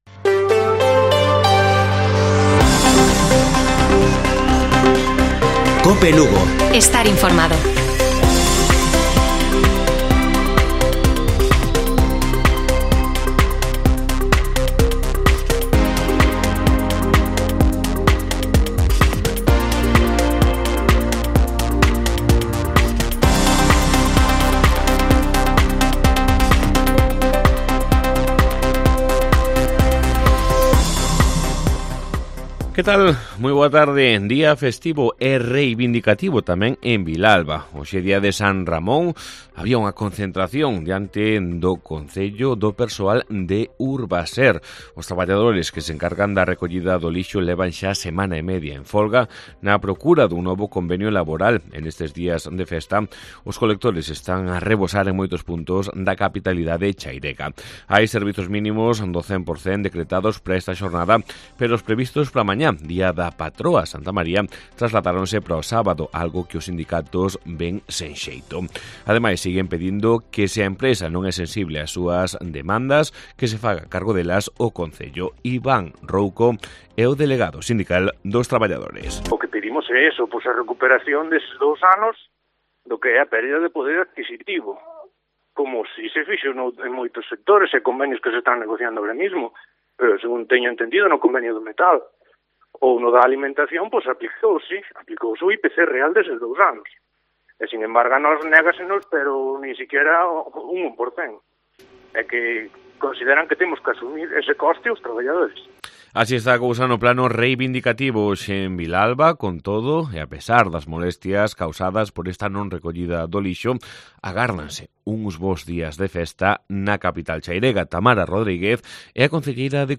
Informativo Mediodía de Cope Lugo. 31 de agosto. 14:20 horas